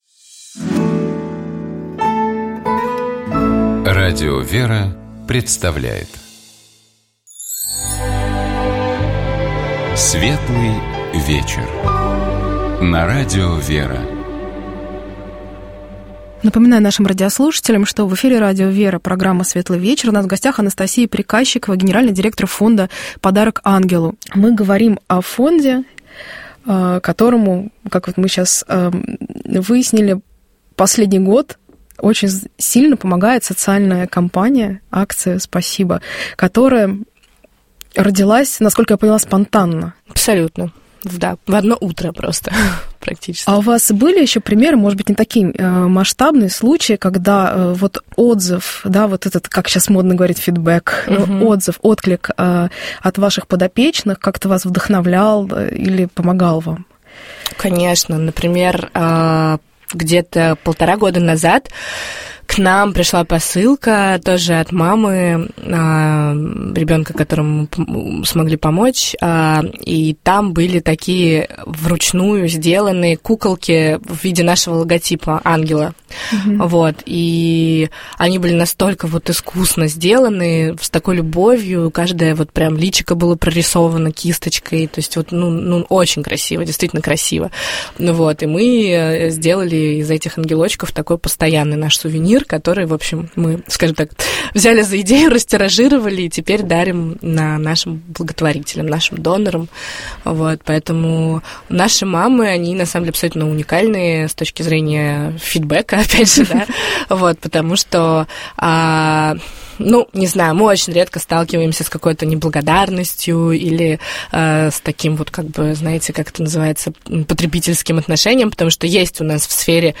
Этот разговор состоялся в рамках бесед «Вера и дело». Мы говорили о проектах фонда «Подарок ангелу», направленных на помощи детям с ДЦП, и о том как удается менять их жизни детей и их близких к лучшему